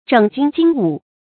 整軍經武 注音： ㄓㄥˇ ㄐㄩㄣ ㄐㄧㄥ ㄨˇ 讀音讀法： 意思解釋： 經：治理。整頓軍隊，經營武備。